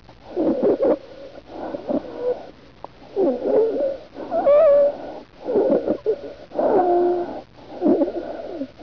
Listen to the lung sounds and try to identify with adventitious breath sounds (Table 32-3 on page 853) and we will discuss in class.
lungsound1.aif